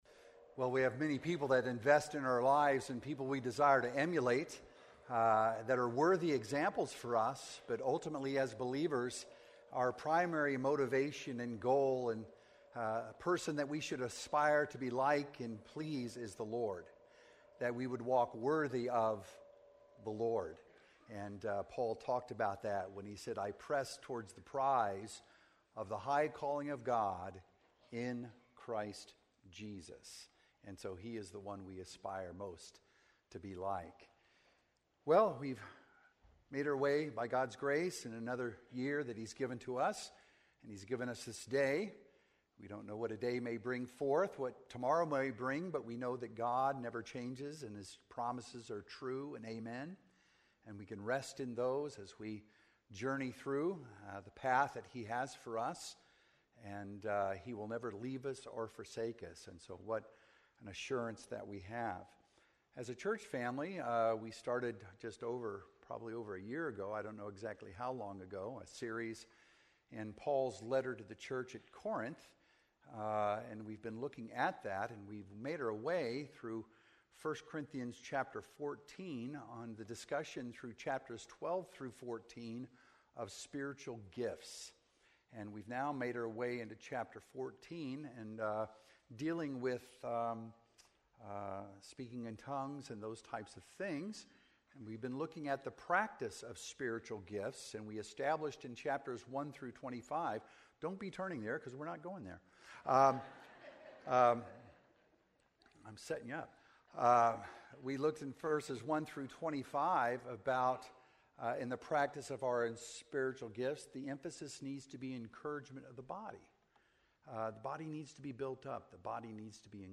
Sermons - Emmanuel Baptist Church
From Series: "Sunday Mornings"